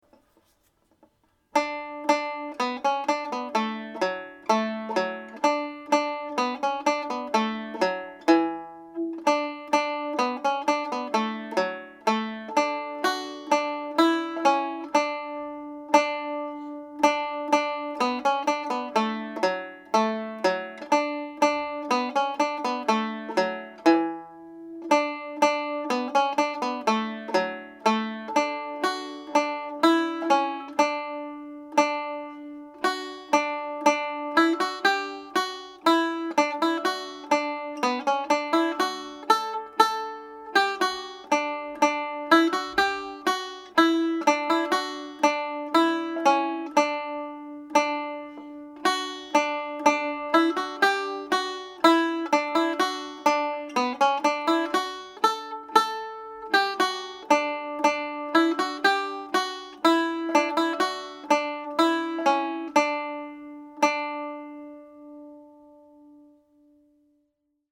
full tune played a little faster